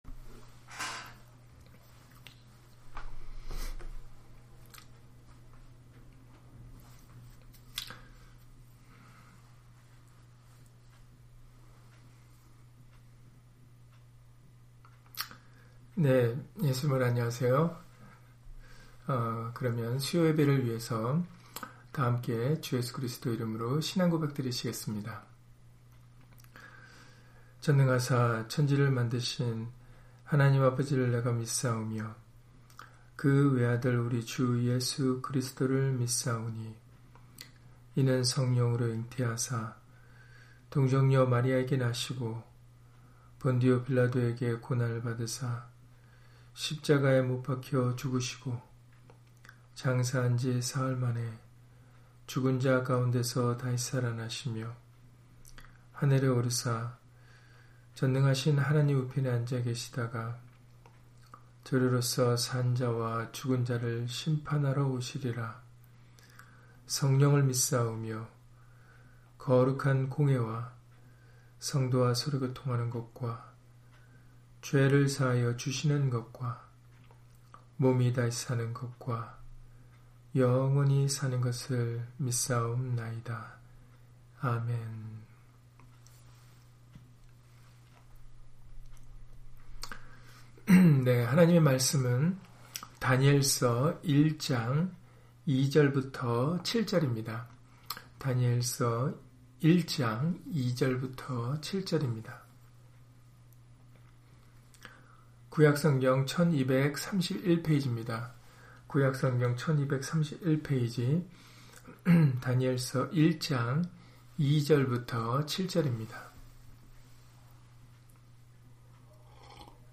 다니엘 1장 2-7절 [다니엘과 세 친구들의 등장] - 주일/수요예배 설교 - 주 예수 그리스도 이름 예배당